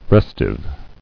[res·tive]